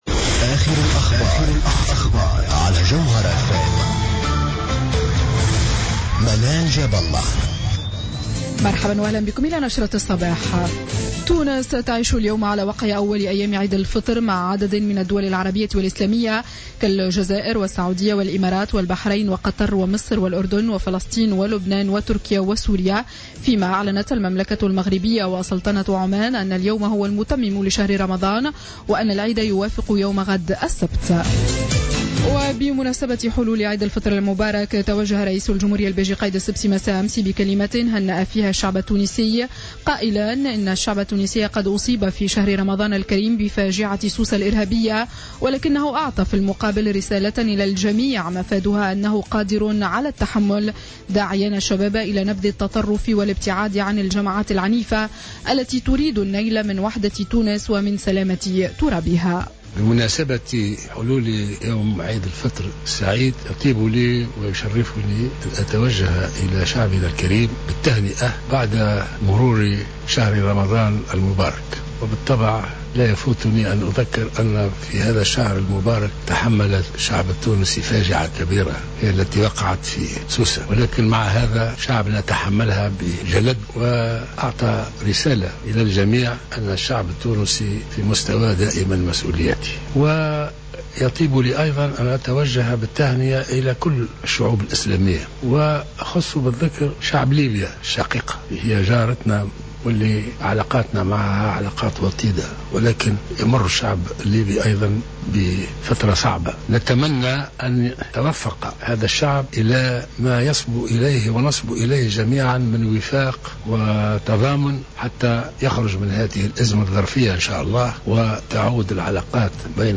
نشرة أخبار السابعة صباحا ليوم الجمعة 17 جويلية 2015